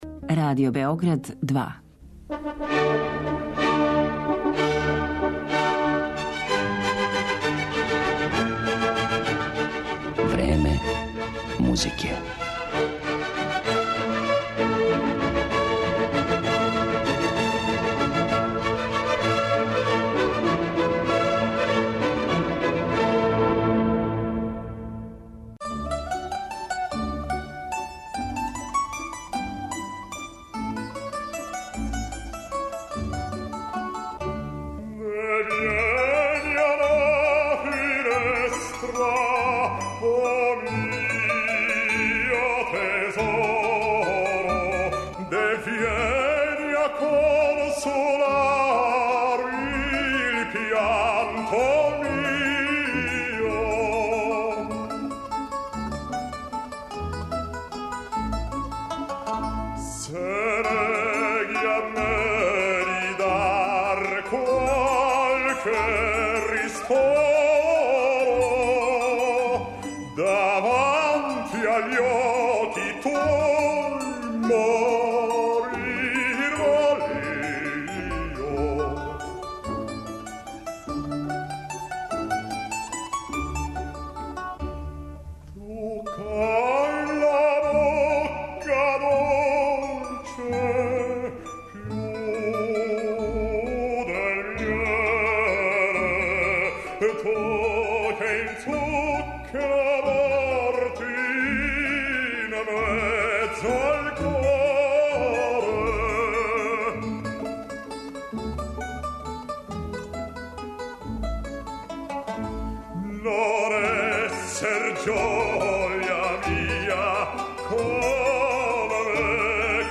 Имаћете прилику да чујете како су његов карактер приказали композитори Глук, Моцарт, Лист, Штраус, Чајковски и Салинен.